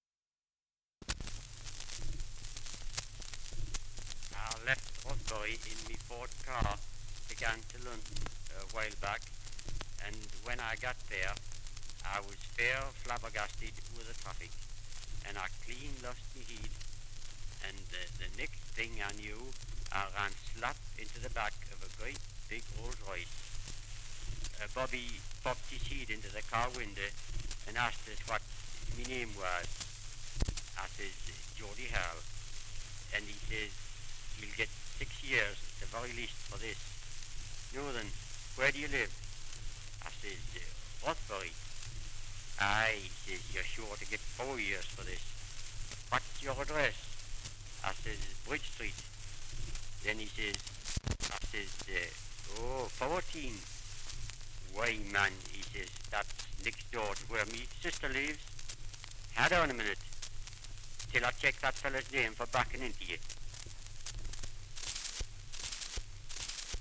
Dialect recording in Rothbury, Northumberland
78 r.p.m., cellulose nitrate on aluminium.